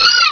-Replaced the Gen. 1 to 3 cries with BW2 rips.
skitty.aif